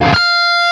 LEAD F 4 LP.wav